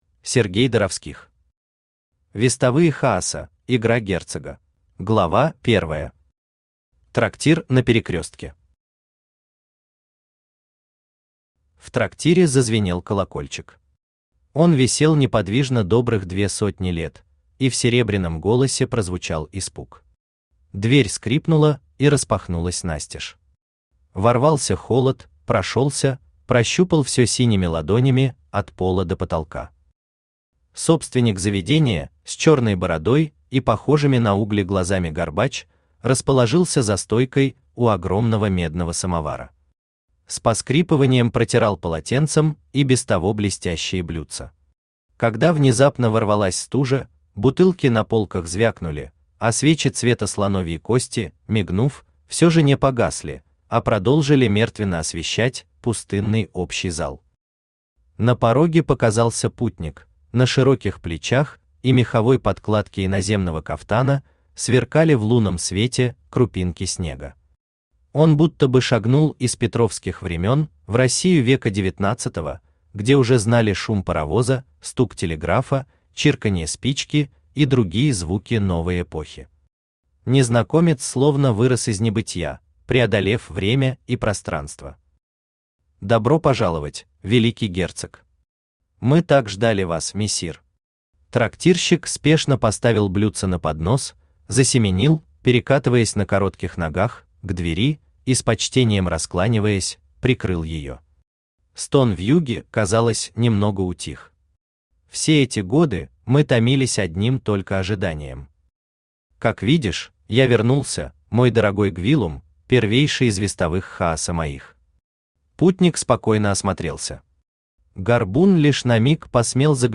Aудиокнига Вестовые Хаоса: Игра герцога Автор Сергей Владимирович Доровских Читает аудиокнигу Авточтец ЛитРес.